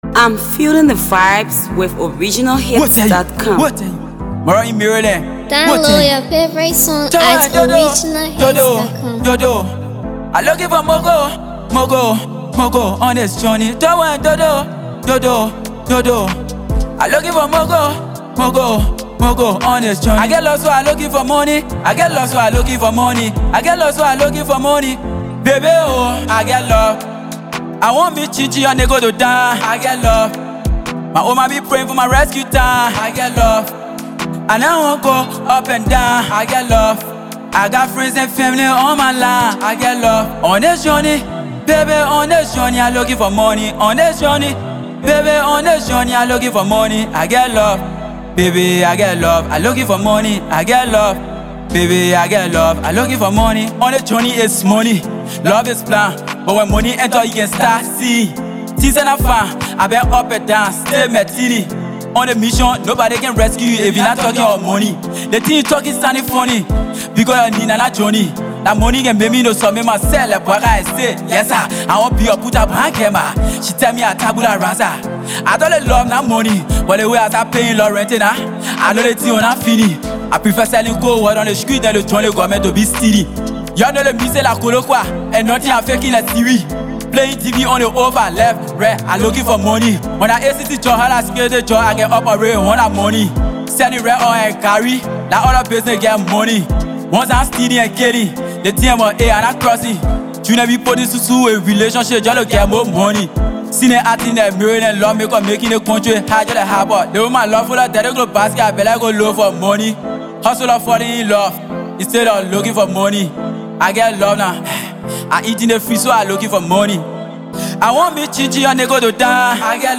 AfroAfro PopHIPCOLATEST PLAYLISTMusic